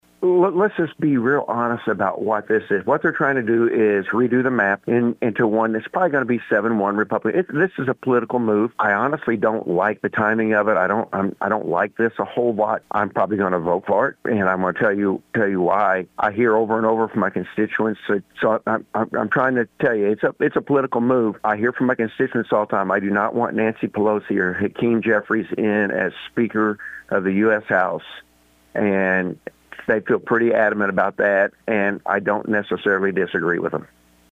Missouri Senator Mike Henderson shared his perspective on the issue.